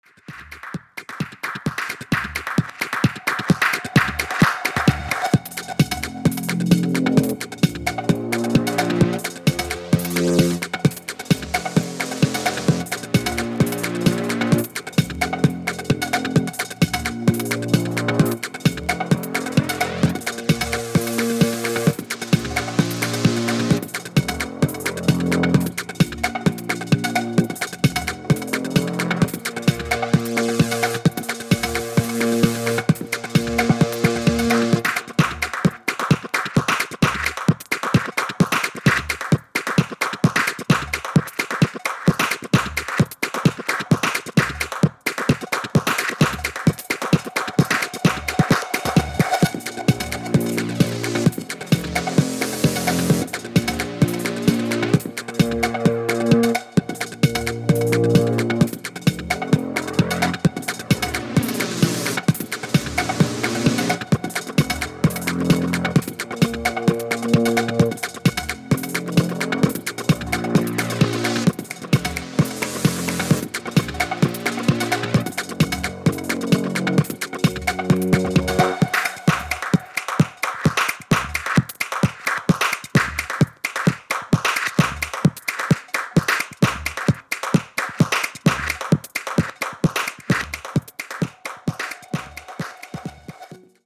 ダビーなサウンドがハマります（笑）